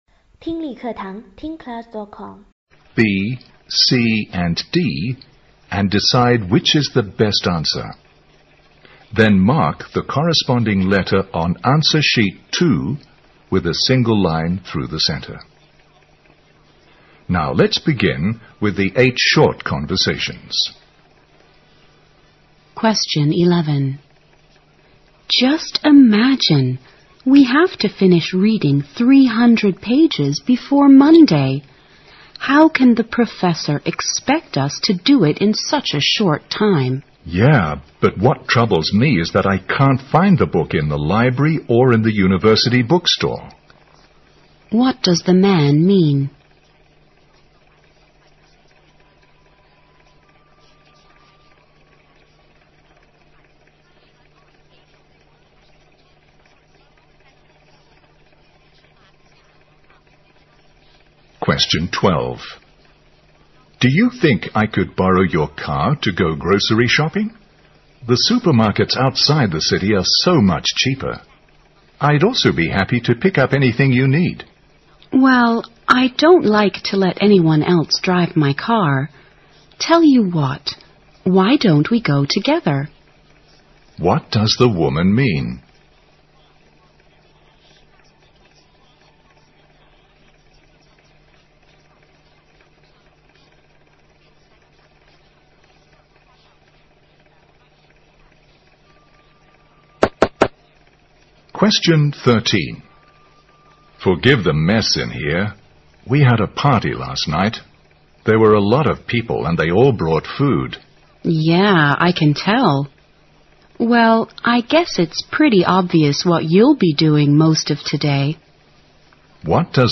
Part III Listening Comprehension